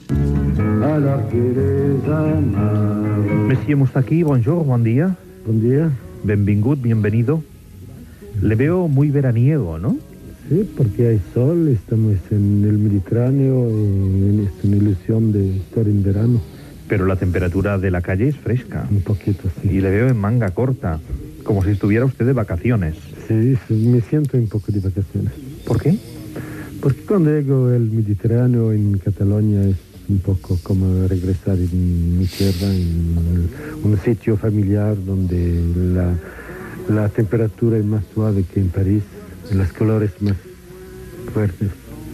Fragment d'una entrevista al cantautor Georges Moustaki.
Info-entreteniment